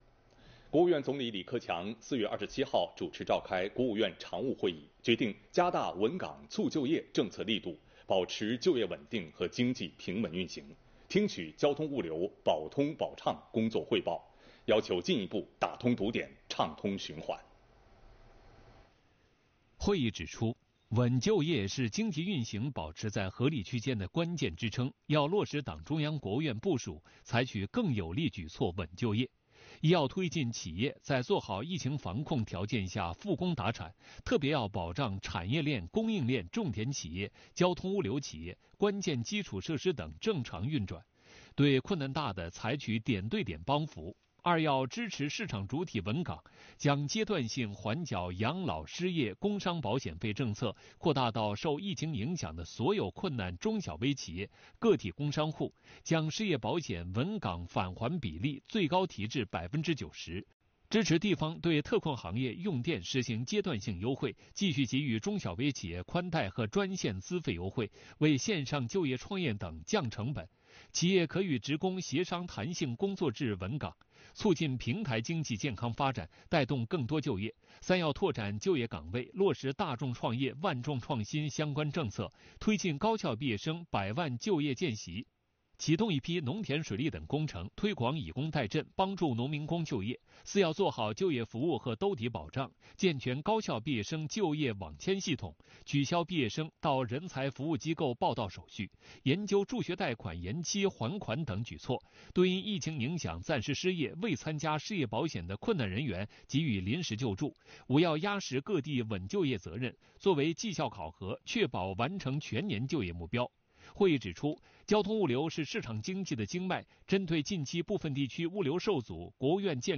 李克强主持召开国务院常务会议